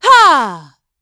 Isolet-Vox_Attack4.wav